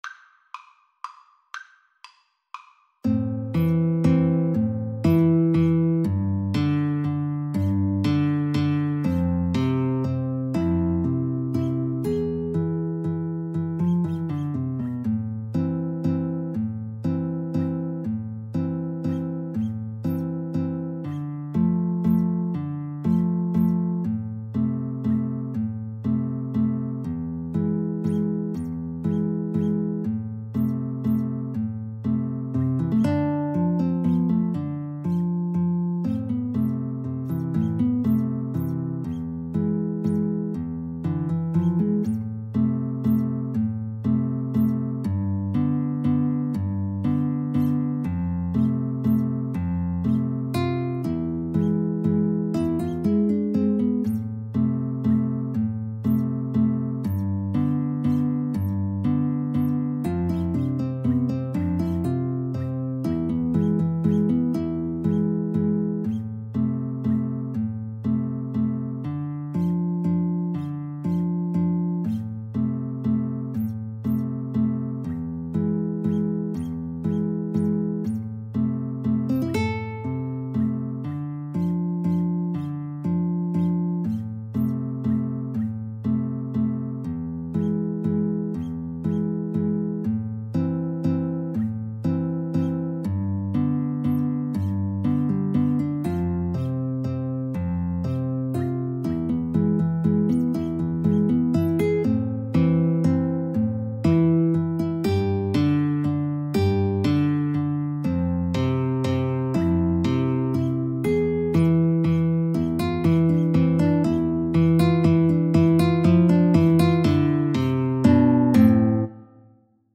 3/4 (View more 3/4 Music)
A minor (Sounding Pitch) (View more A minor Music for Guitar Trio )
Slow Waltz .=40
Traditional (View more Traditional Guitar Trio Music)